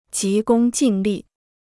急功近利 (jí gōng jìn lì) Free Chinese Dictionary